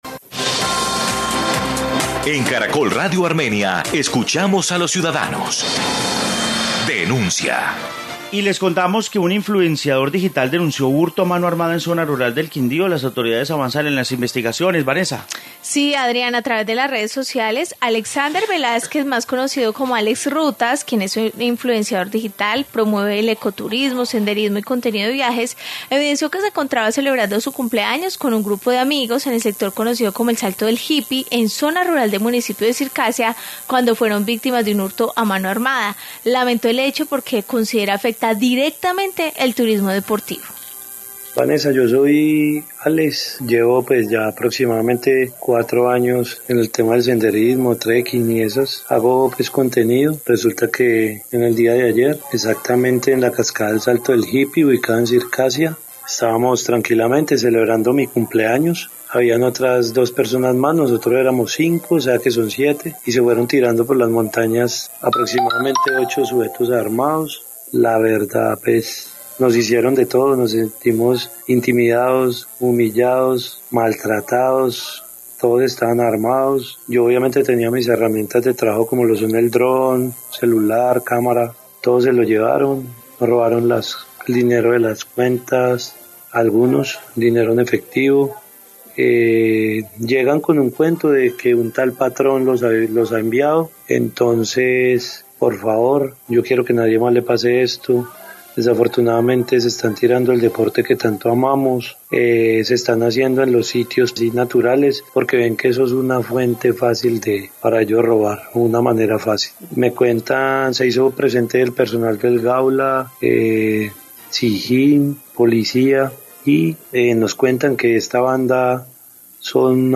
Informe hurto en Circasia